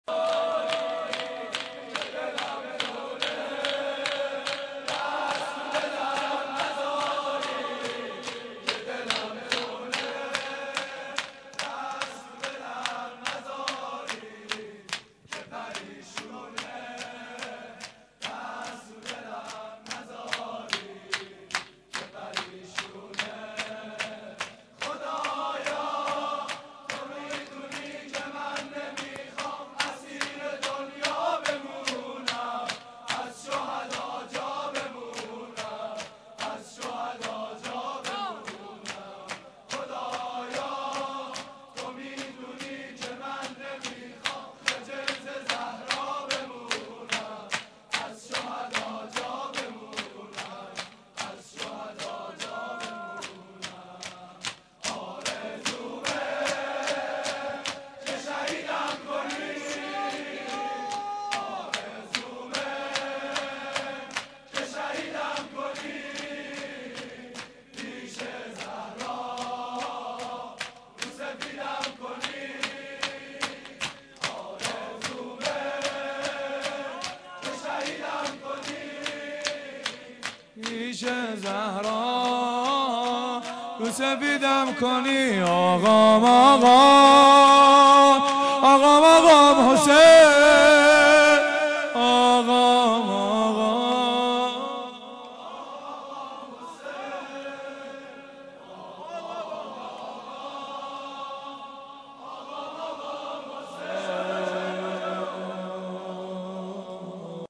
شور
شور.mp3